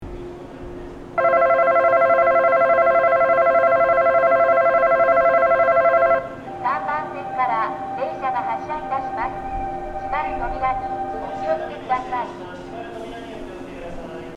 一部風が強い日に収録した音声があるので、風音が被っている音声があります。
発車ベル
5秒鳴動です。
発車放送は女性の放送です。